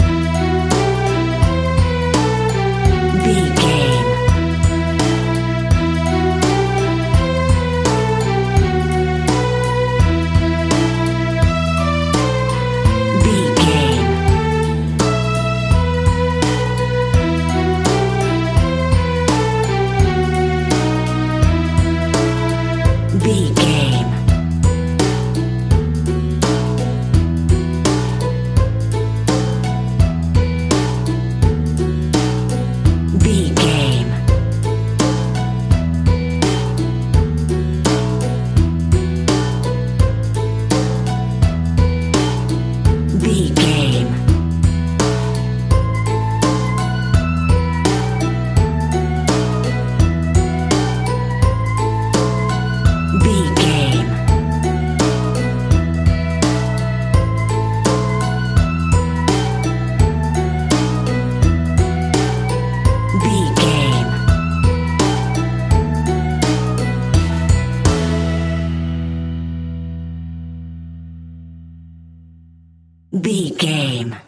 Aeolian/Minor
instrumentals
fun
childlike
happy
kids piano